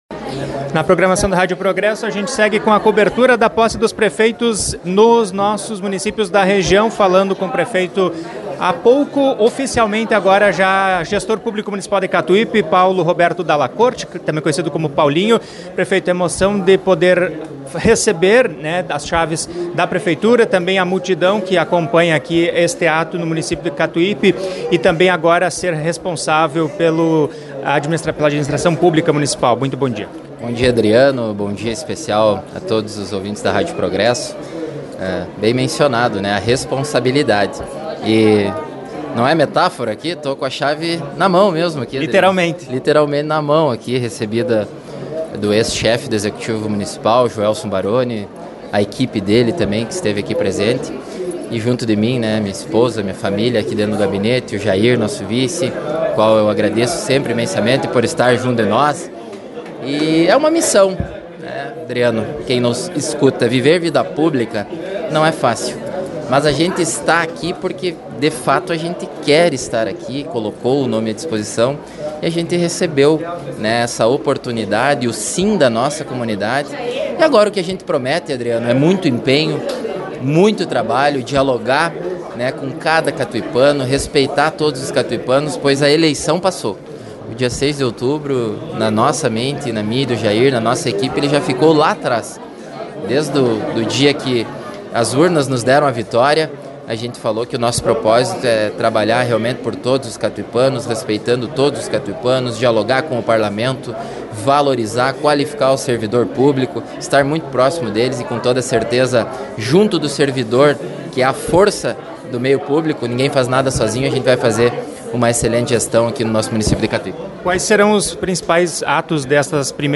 Confira a entrevista do prefeito e vice catuipanos: